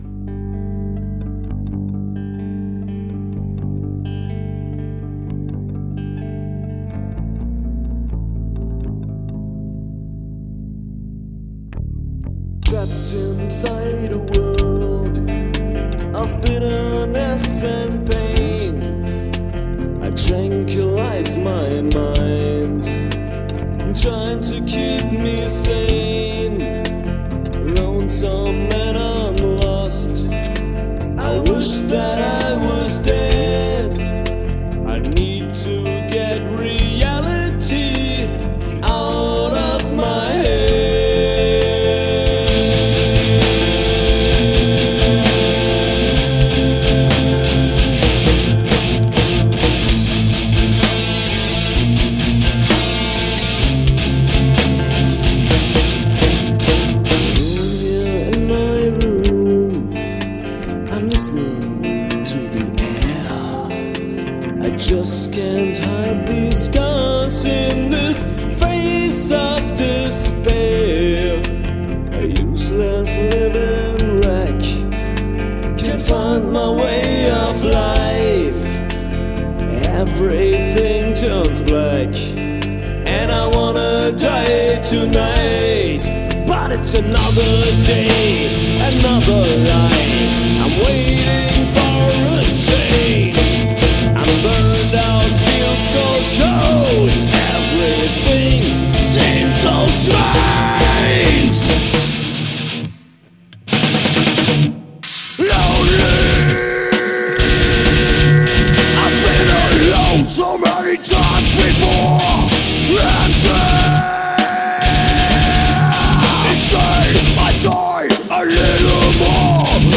24-Spur/Studio